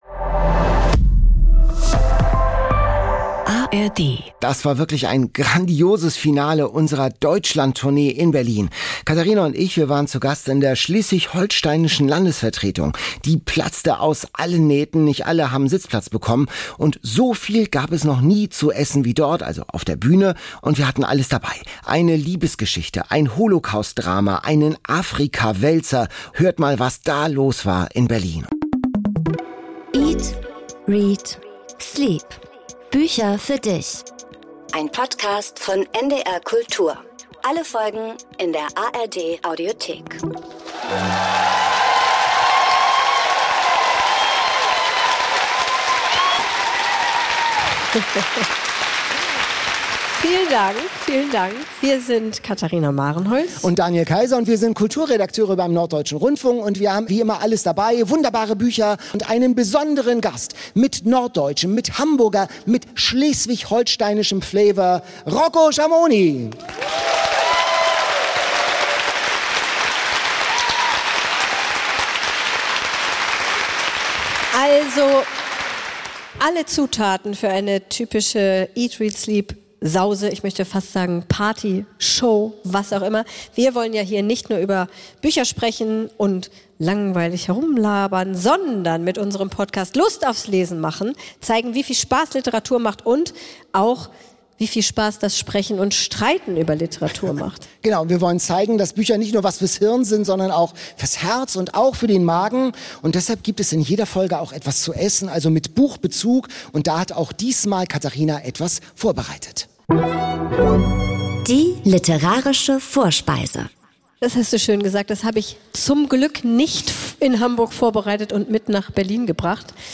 Beste Stimmung in Berlin! Bücher zum Verlieben, Erdbeer-Suprise mit Sahne - und das Publikum reüssiert im Quiz.